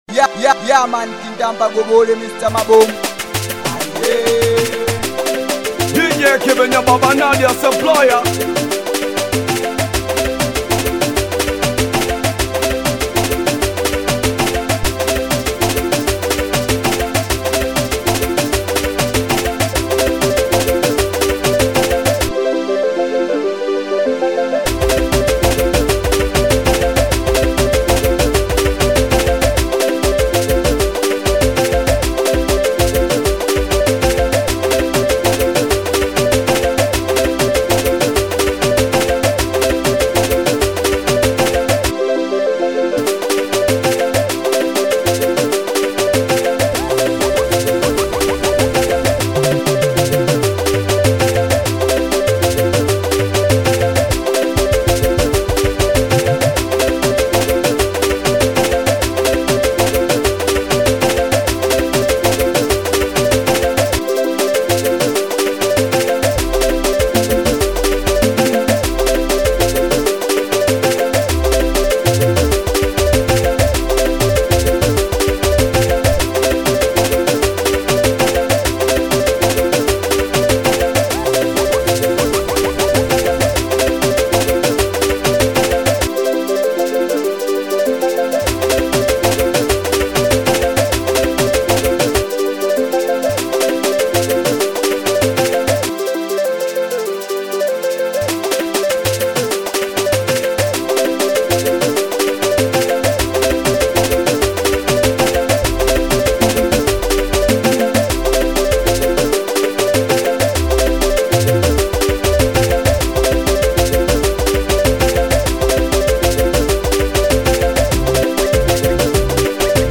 DOWNLOAD BEAT SINGELI
BEAT ZA SINGELI